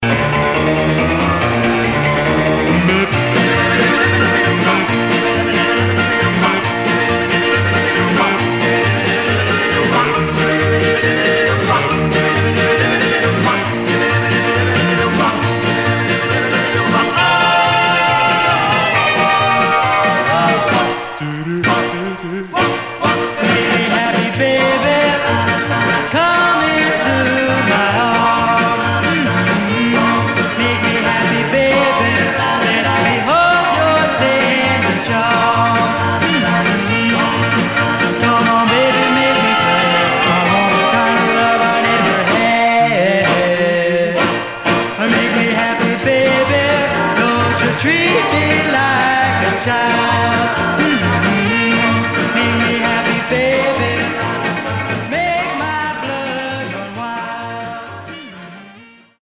Surface noise/wear Stereo/mono Mono
Male Black Groups